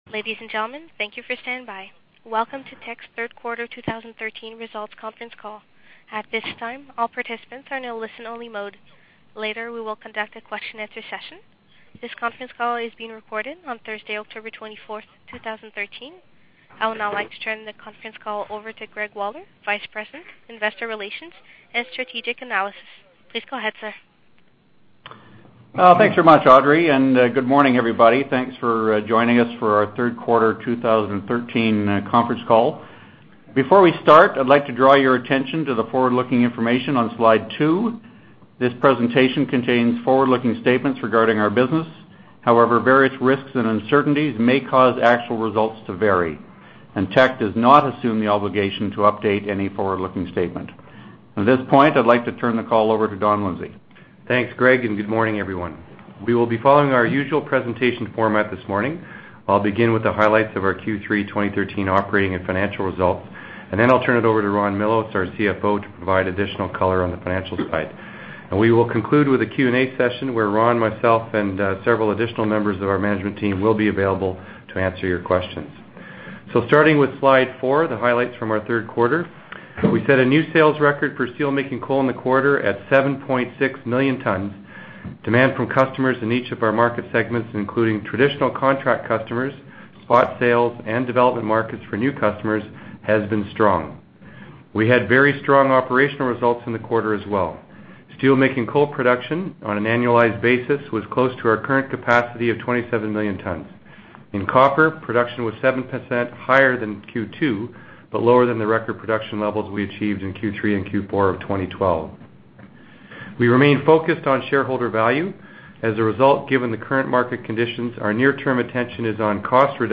q3-2013-conference-call-audio.mp3